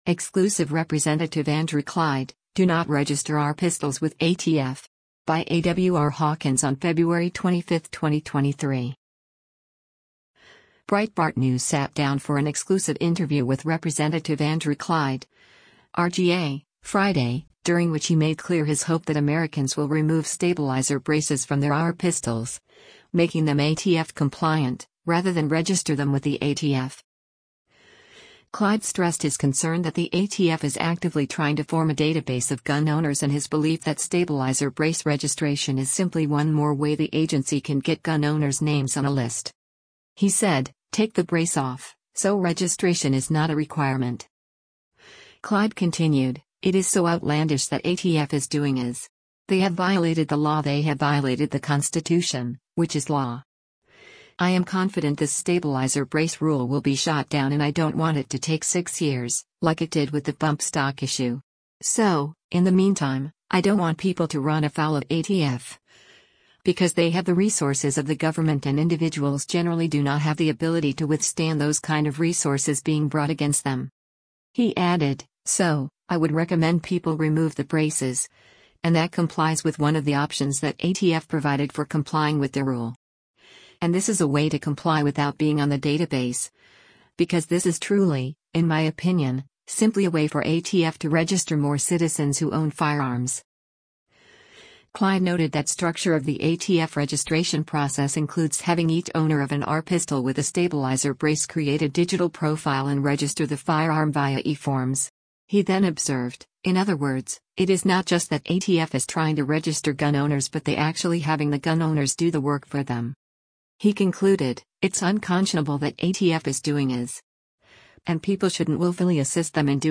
Breitbart News sat down for an exclusive interview with Rep. Andrew Clyde (R-GA) Friday, during which he made clear his hope that Americans will remove stabilizer braces from their AR pistols, making them ATF compliant, rather than register them with the ATF.